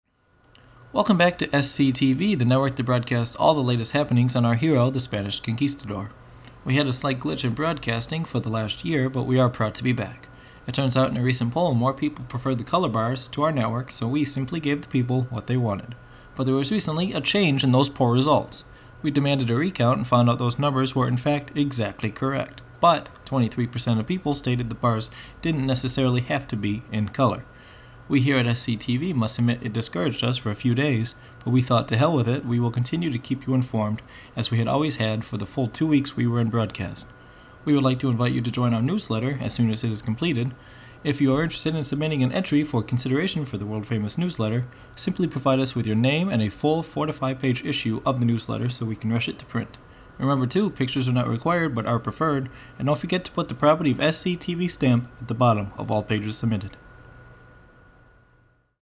The Spanish Conquistador is an ongoing audio comedy series presented by Wayward Orange.